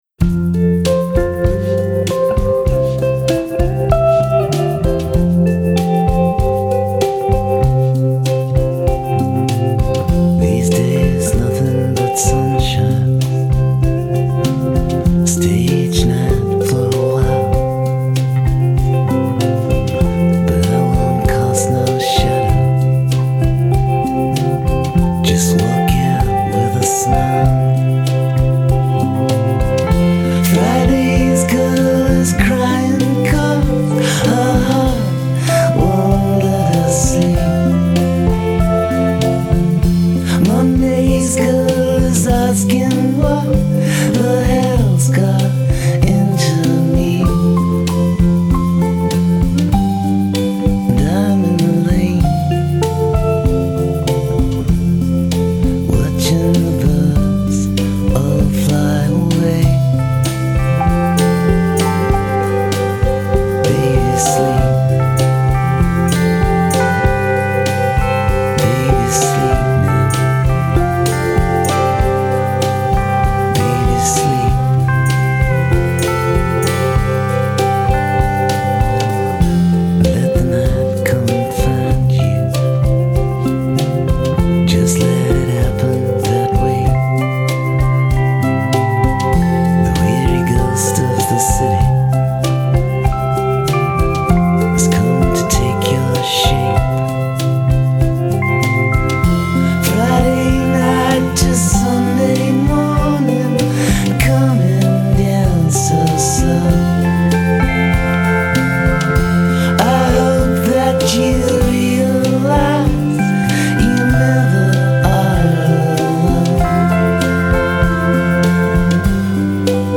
Maturo e composto, colmo di eleganza